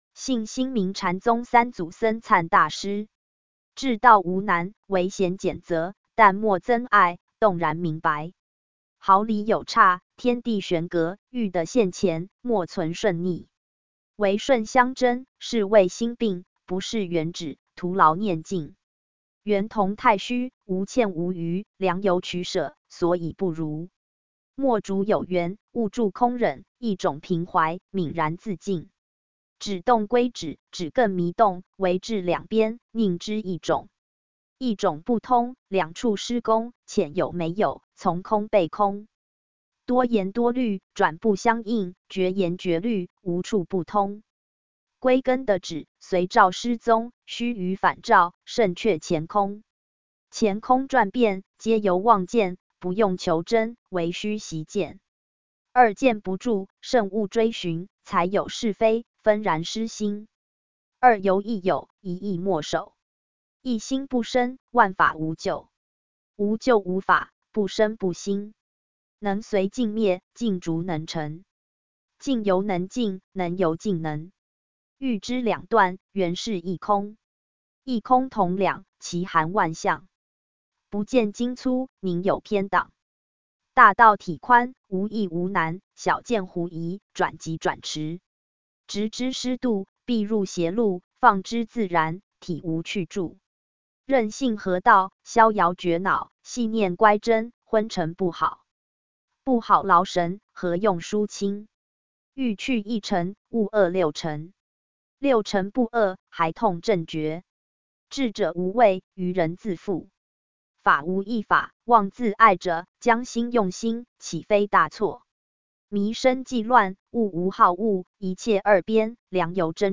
文字轉語音版